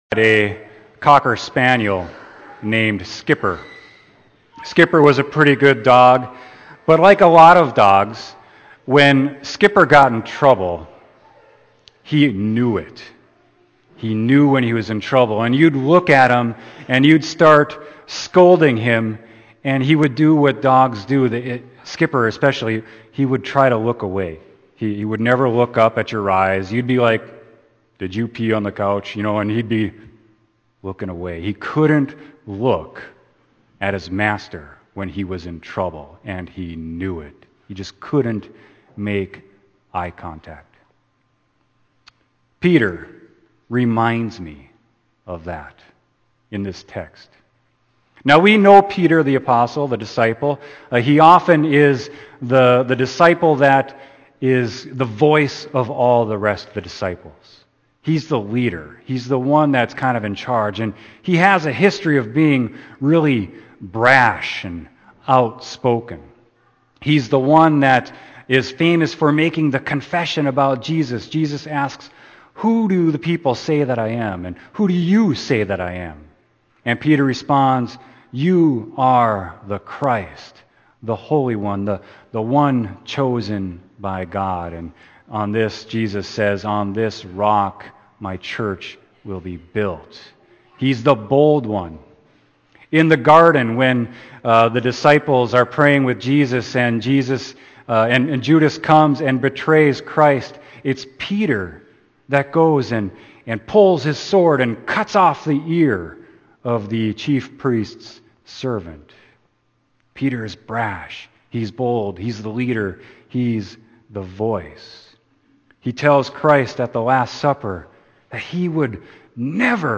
Sermon: Luke 22.54-62